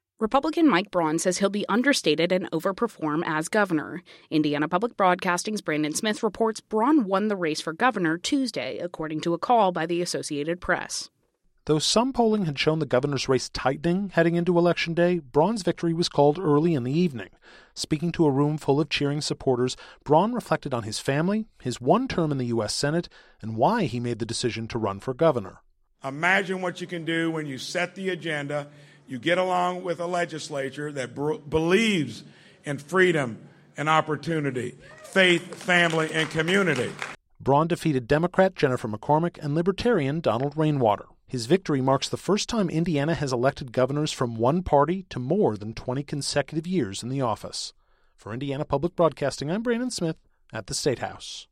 Speaking to a room full of cheering supporters, Braun reflected on his family, his one term in the U.S. Senate, and why he made the decision to run for governor .